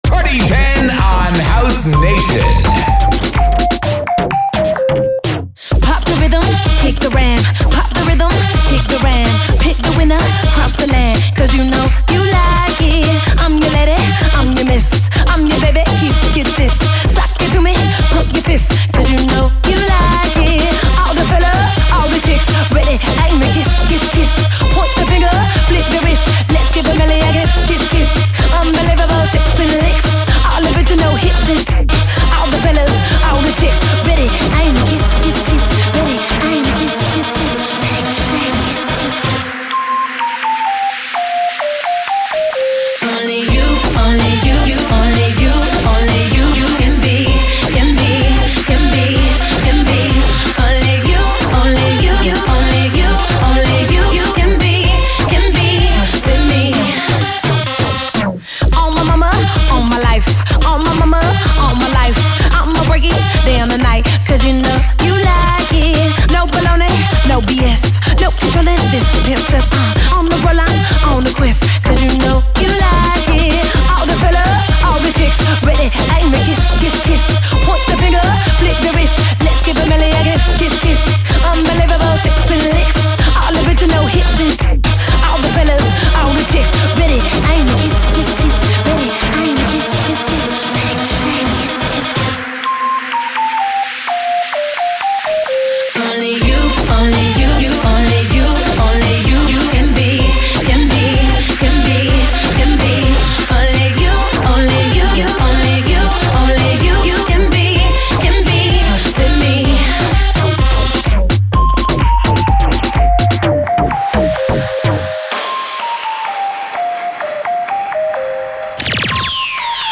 New cutting edge house music